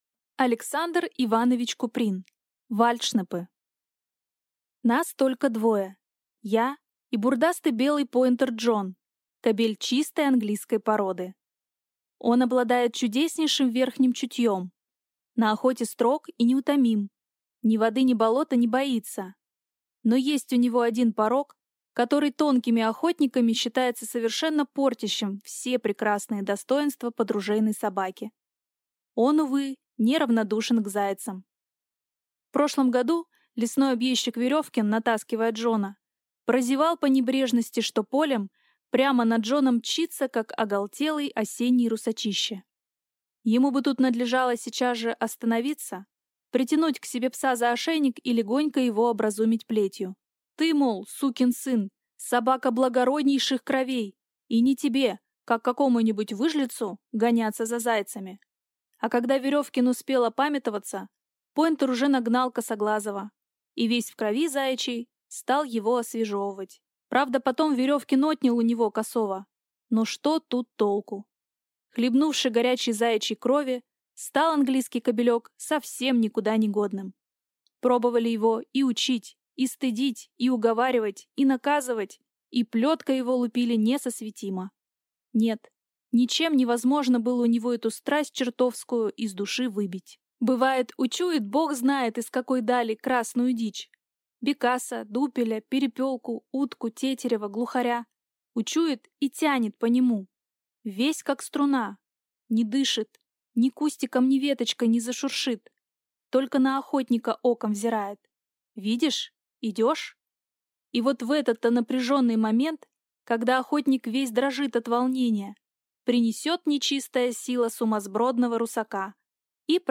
Аудиокнига Вальдшнепы | Библиотека аудиокниг